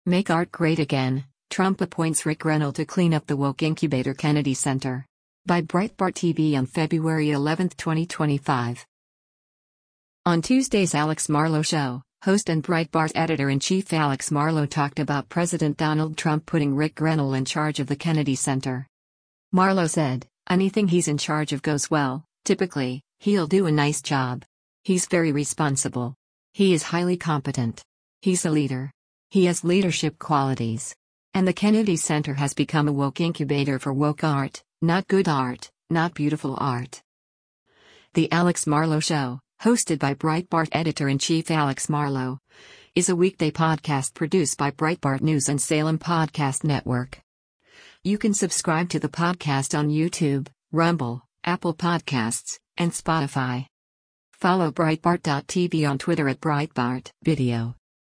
On Tuesday’s “Alex Marlow Show,” host and Breitbart Editor-in-Chief Alex Marlow talked about President Donald Trump putting Ric Grenell in charge of the Kennedy Center.